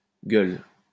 German: Göhl [ˈɡøːl]; French: Gueule [ɡœl]